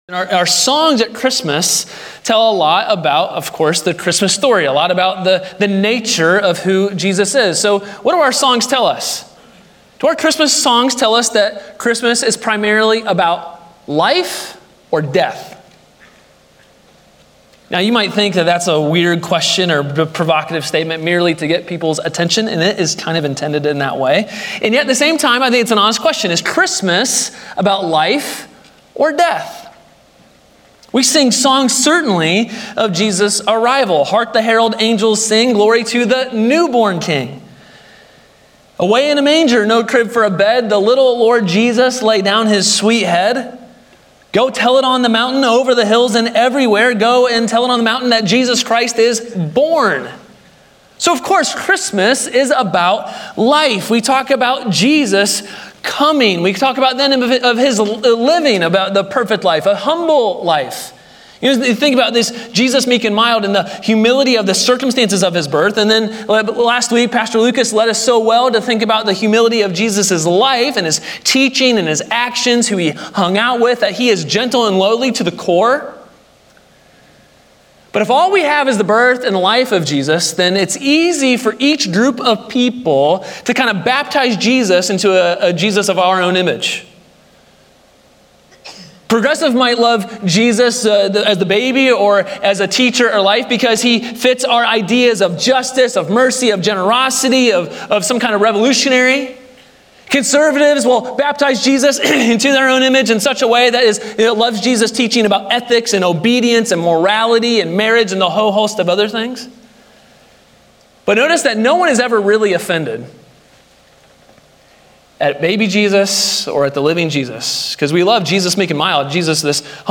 Sermon Slides Worship Program Share Via Facebook Tweet Link Share Link Share Via Email